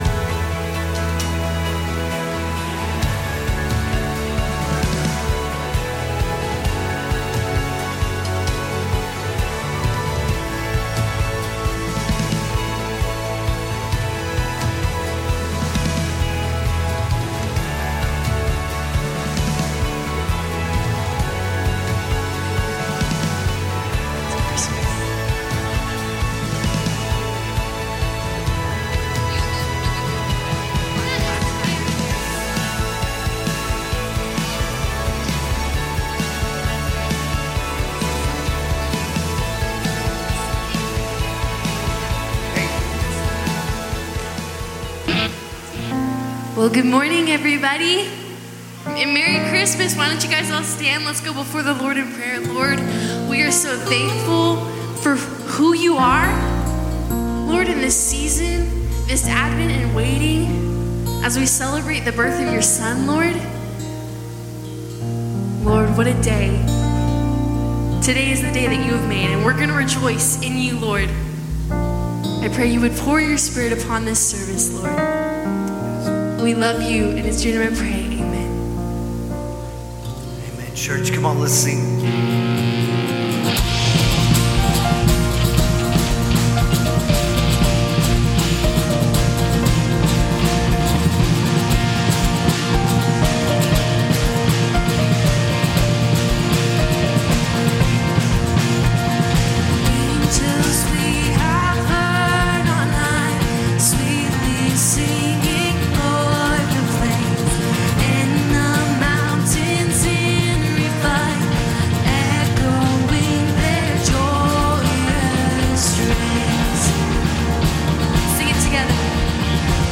Calvary Knoxville Sunday AM Live!